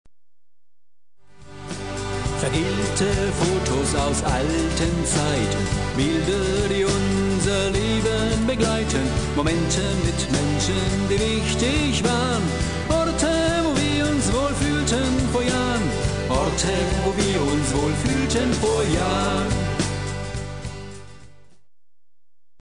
ein Ohrschmeichler